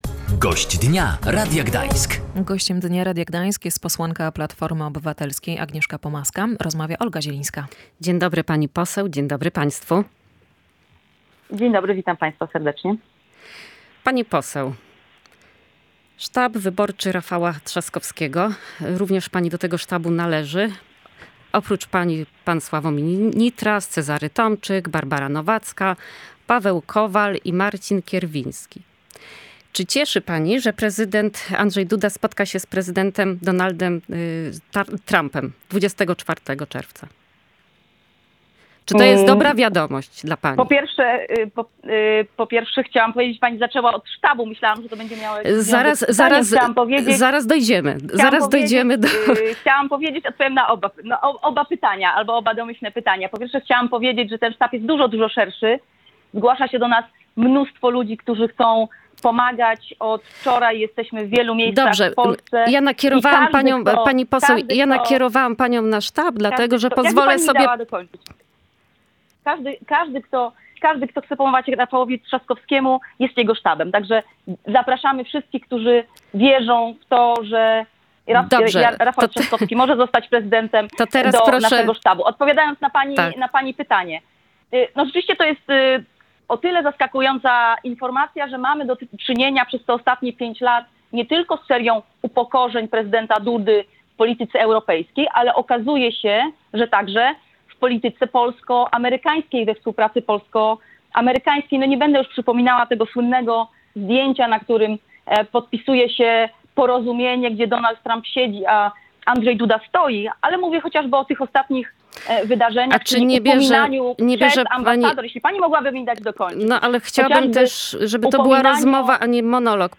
Gościem Dnia Radia Gdańsk była pomorska posłanka Platformy Obywatelskiej i członek sztabu Rafała Trzaskowskiego - Agnieszka Pomaska.